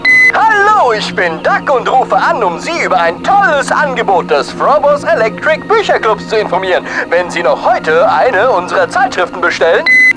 WARNUNG: Die deutsche Fassung ist unverhältnismäßig laut eingebunden worden, entsprechend sind auch die Samples teilweise recht laut.
Die Übersteuerungen sind echt ziemlich krass, da hätte man wirklich bei der Abmischung drauf achten können.